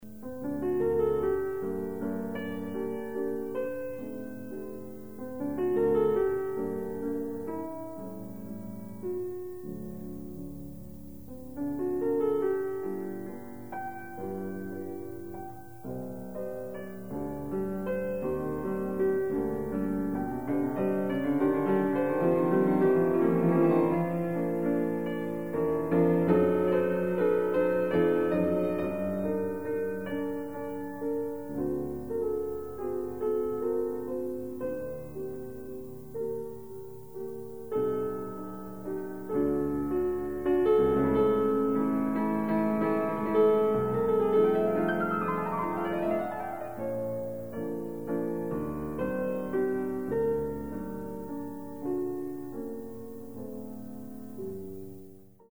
Additional Date(s)Recorded September 11, 1977 in the Ed Landreth Hall, Texas Christian University, Fort Worth, Texas
Ballades (Instrumental music)
Suites (Piano)
Short audio samples from performance